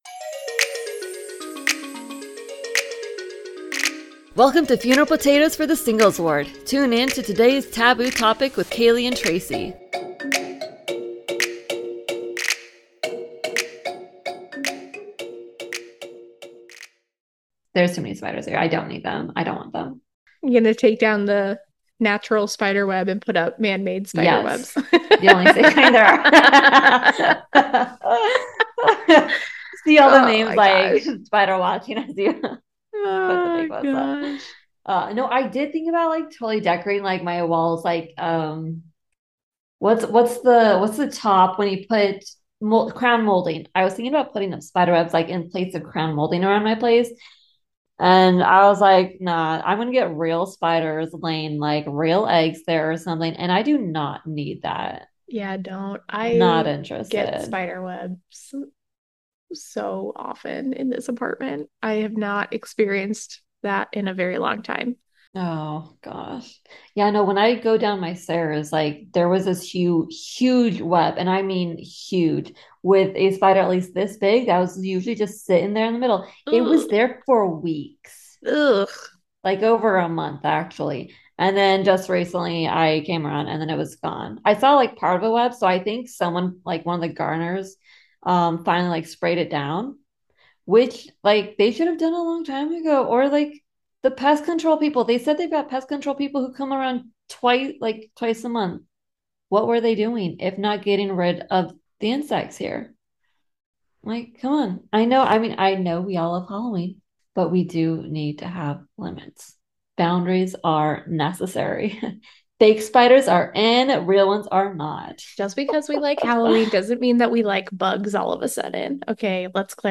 Two Mormon women create a safe space for honest, spiritual, and humorous religious conversations about being a single adult in the Church of Jesus Christ of Latter-day Saints.
A religious comedy podcast for anyone exploring their faith.